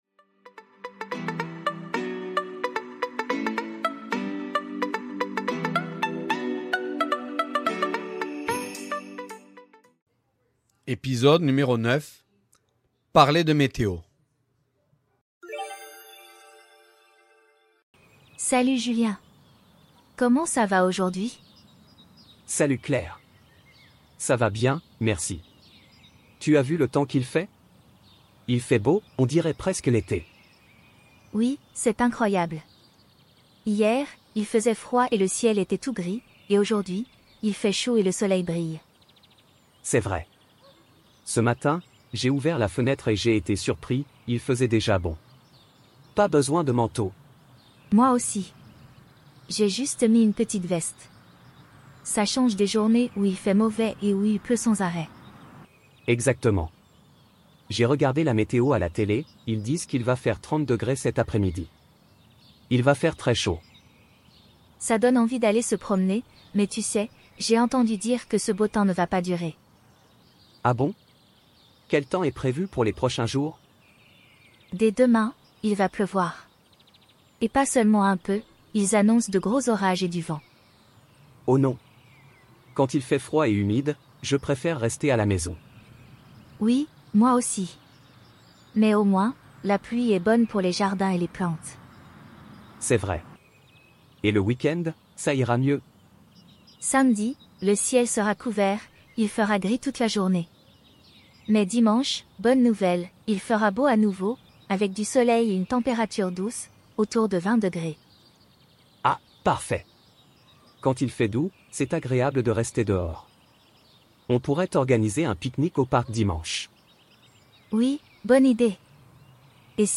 Voici un petit dialogue pour les débutants. Avec cet épisode, vous allez apprendre quelques expressions pour parler de météo.
009-Podcast-dialogues-Parler-de-meteo.mp3